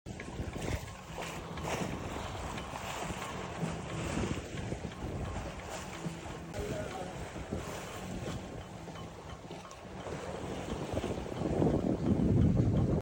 The sounds of sailboats ✌❤⛵ sound effects free download
There is something peaceful, unexplainable about the sounds you hear aboard a sailboat.